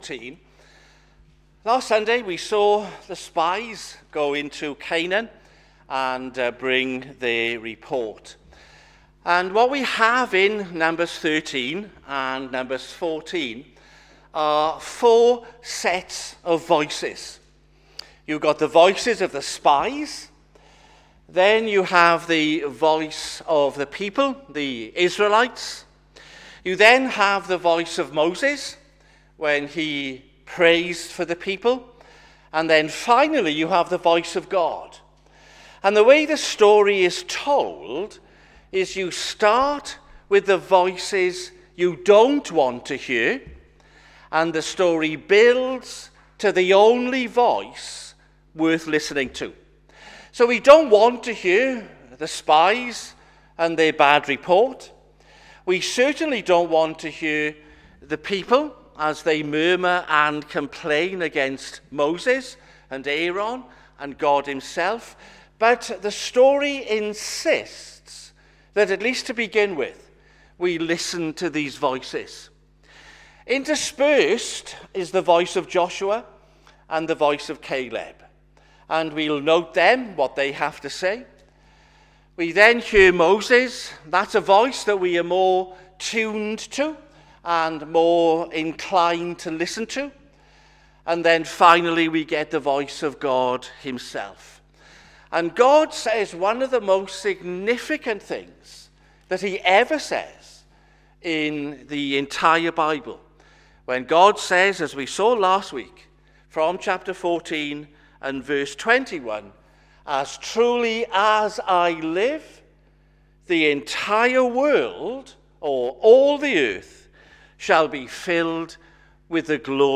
Sermons
sermon-2026-c-March-1-am.mp3